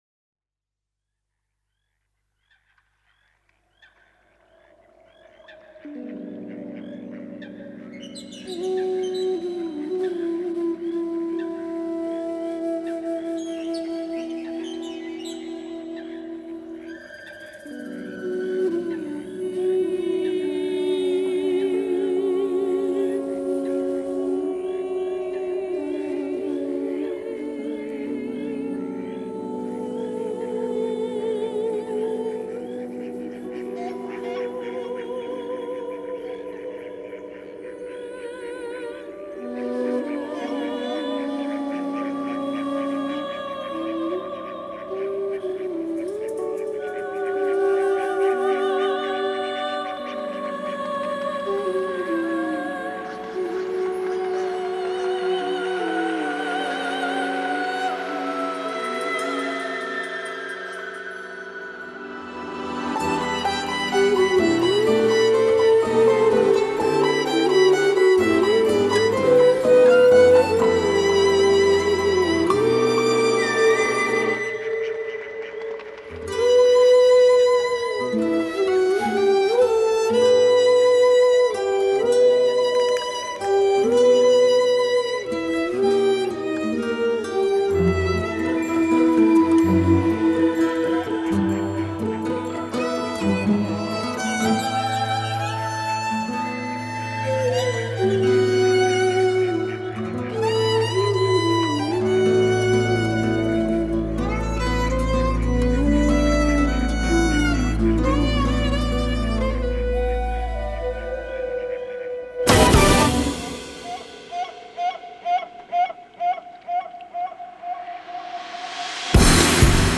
lead vocals, guitar, slap bass
saxes
violin
bansuri flutes